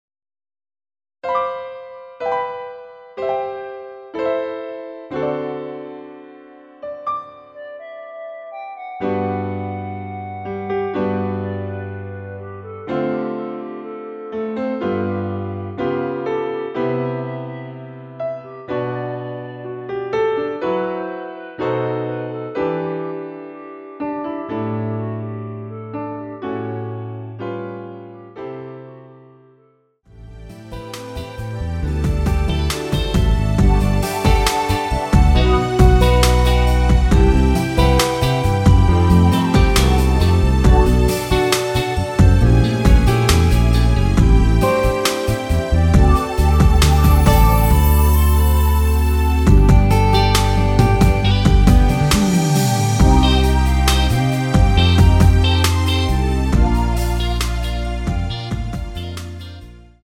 원키에서(+2)올린 멜로디 포함된 MR입니다.
노래방에서 노래를 부르실때 노래 부분에 가이드 멜로디가 따라 나와서
앞부분30초, 뒷부분30초씩 편집해서 올려 드리고 있습니다.
중간에 음이 끈어지고 다시 나오는 이유는